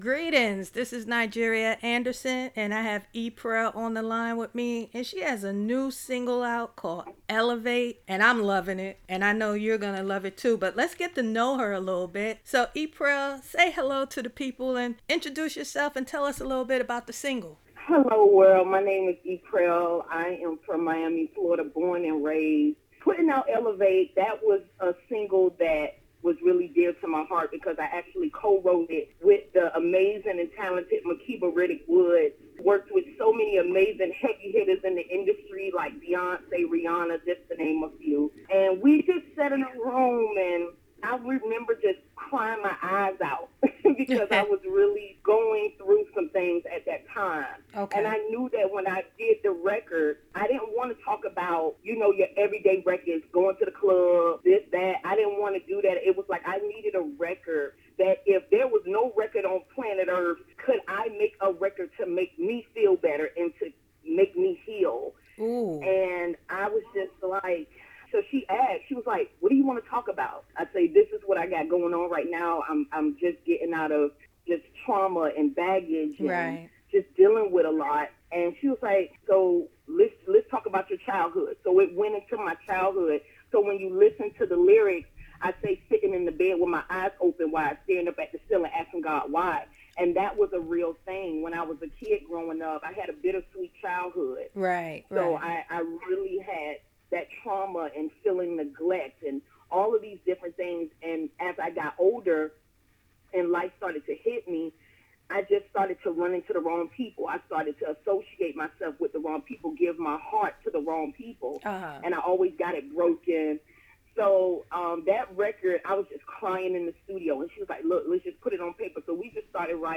This is one of those interviews you should listen to and obtain some gems on how to overcome hindrances.